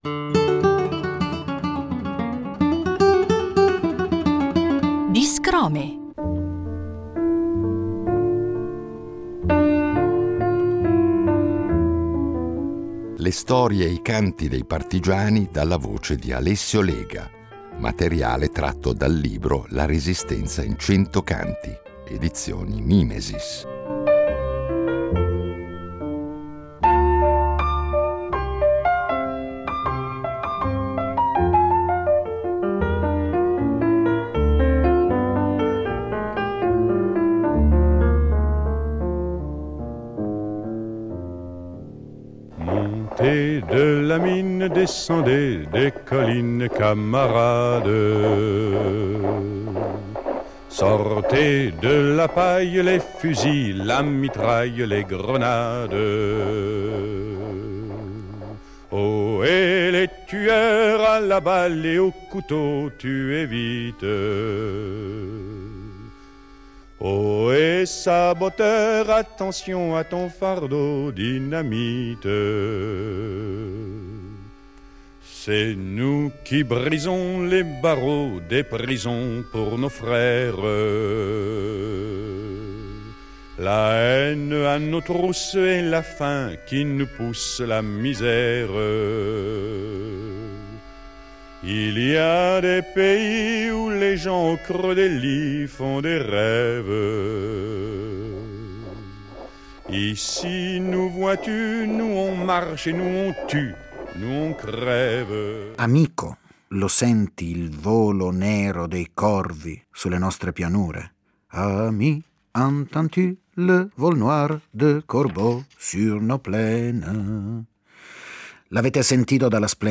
Le storie e le canzoni della Resistenza dalla voce e dalla chitarra
cantautore e cantastorie oltre che profondo conoscitore e studioso dei rapporti tra la canzone e la storia politica e sociale.